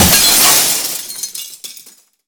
glass_smashable_large_break_02.wav